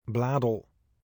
sebutan (bantuan·info)) merupakan sebuah gemeente Belanda yang terletak di wilayah Noord Brabant.